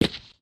test_concrete01.ogg